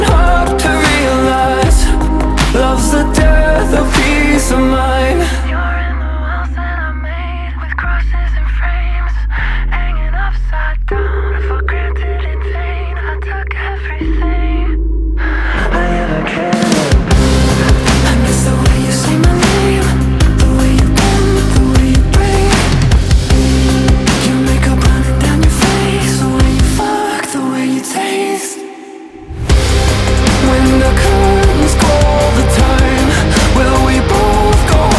Жанр: Рок / Метал